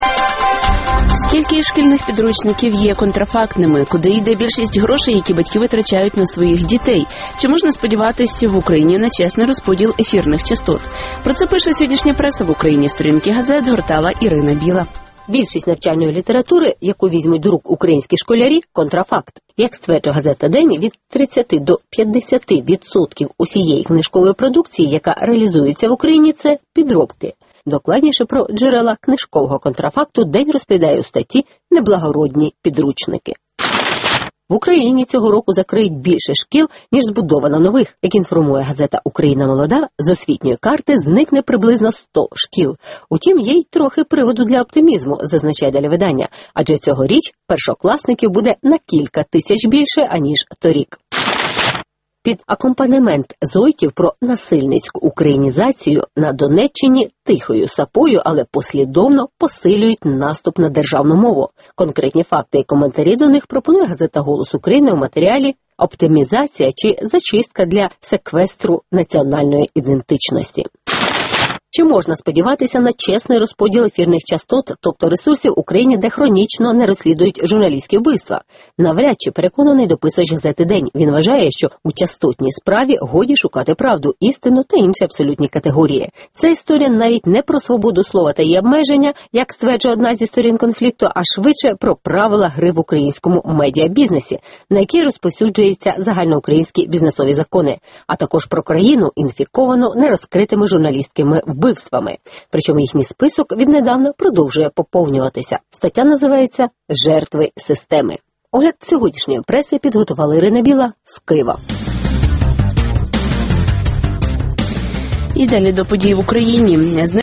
Чи можна сподіватись в Україні на чесний розподіл ефірних частот (огляд преси)